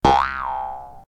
bounce1.ogg